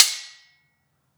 Sword Clash.wav